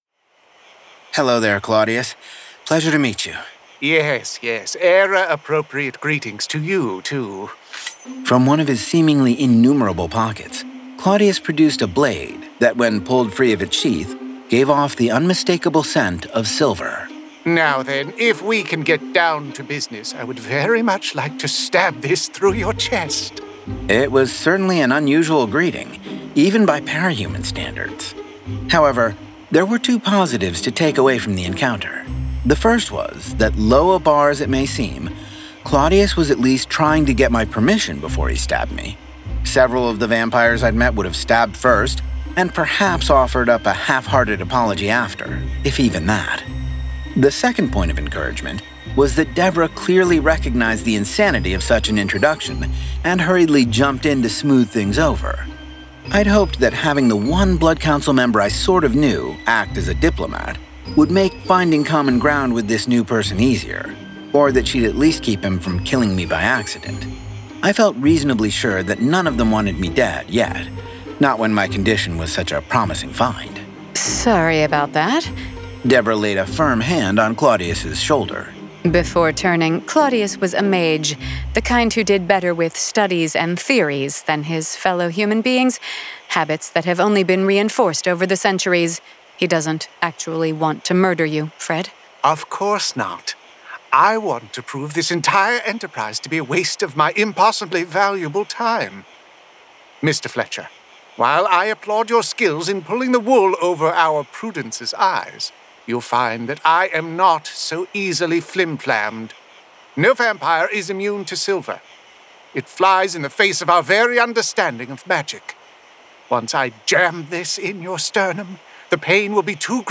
Full Cast. Cinematic Music. Sound Effects.
[Dramatized Adaptation]
Genre: Urban Fantasy